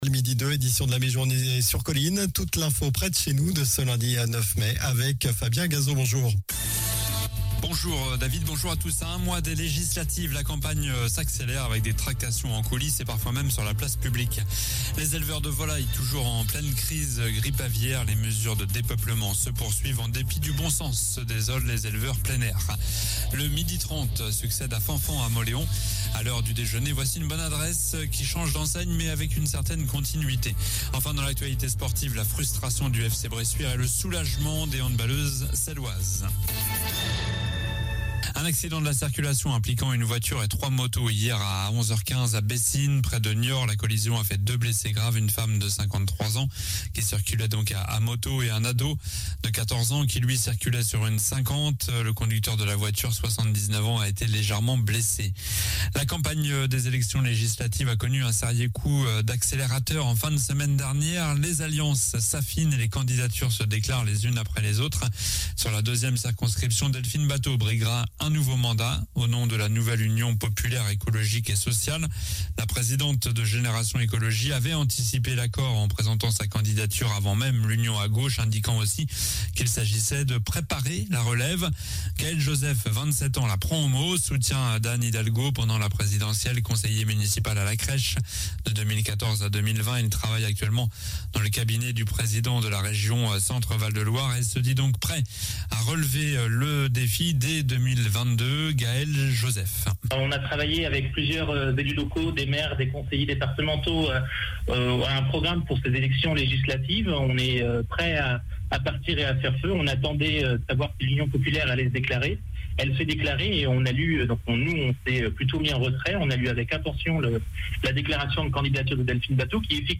Journal du lundi 09 mai (midi)